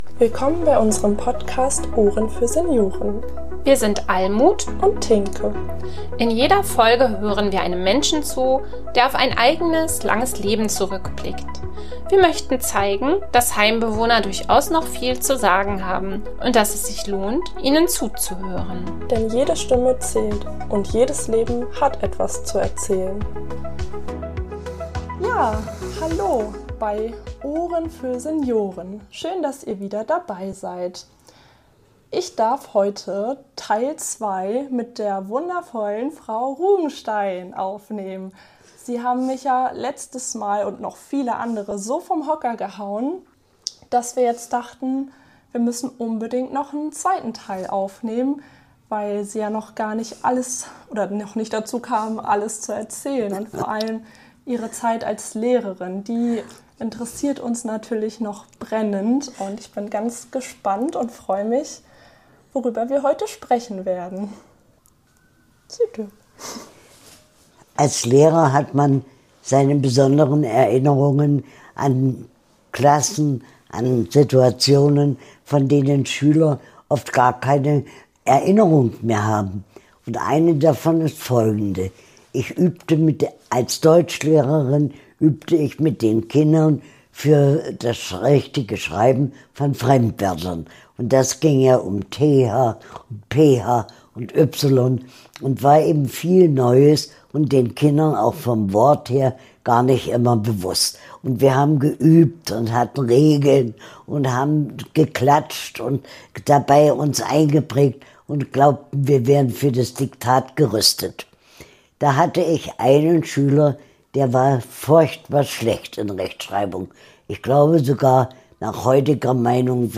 Jahrzehnte im Klassenzimmer und unzählige Geschichten im Herzen. Unsere leidenschaftliche Bewohnerin erzählt uns mitreißend von ihrer Zeit als Lehrerin, vom Umgang mit Kindern und Kritik am Schulsystem. Mit starker Stimme und eindrucksvoller Haltung lenkt sie den Blick auf das, was wirklich zählt.